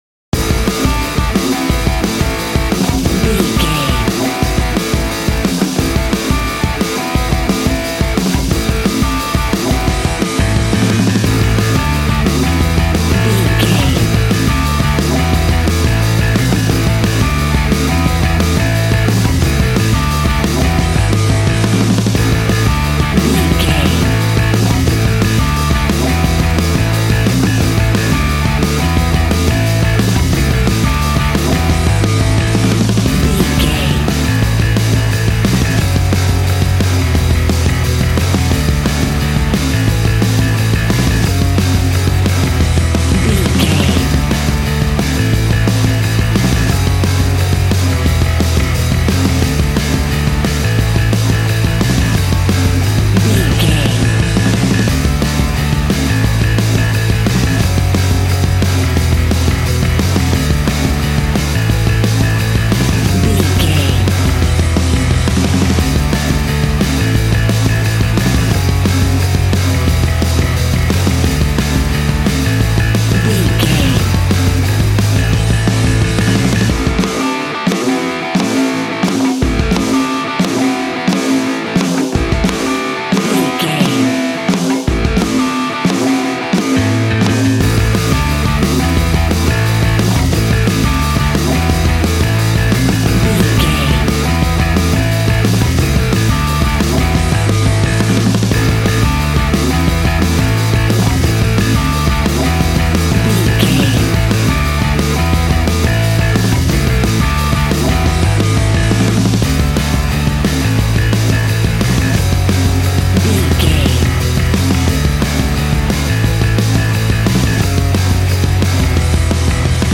Ionian/Major
groovy
powerful
electric organ
electric guitar
bass guitar